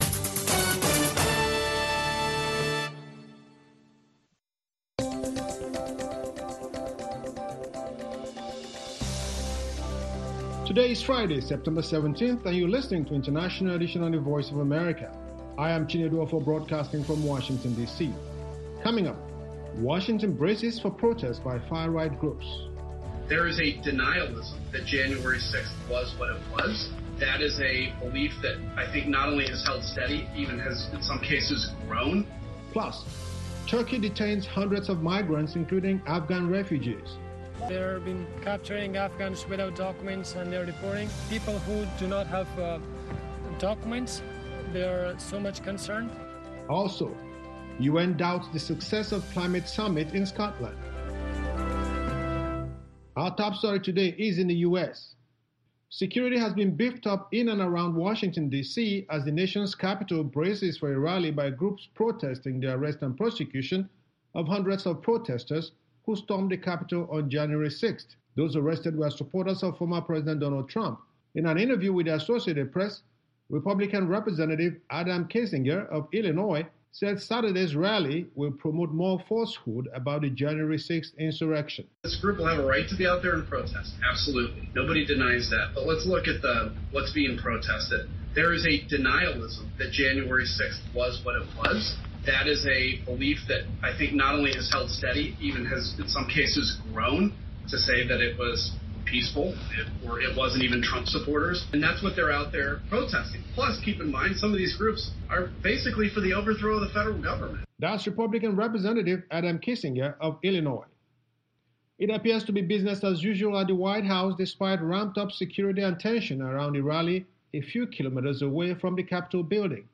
VOA’s International Edition brings the world together with U.S. perspectives. Experts, analysts, social media views and newsmakers themselves help tell today’s stories everyone wants to hear.